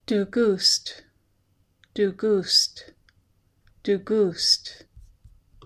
In Northern Haida there is X̱aad Kíl, also known as the Old Massett dialect of the Haida language.